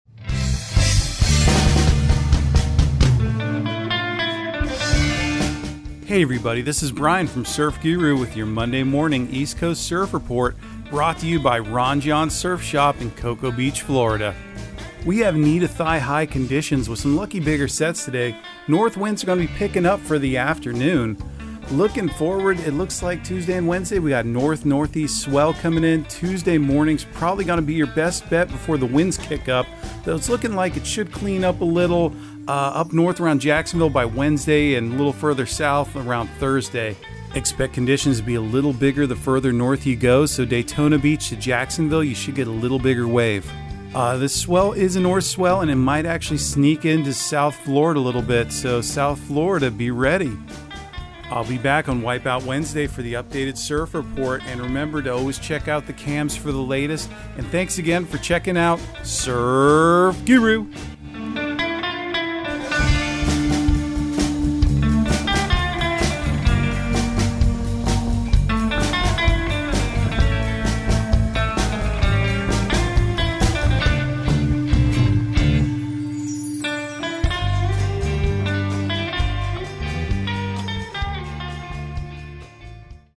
Surf Guru Surf Report and Forecast 10/03/2022 Audio surf report and surf forecast on October 03 for Central Florida and the Southeast.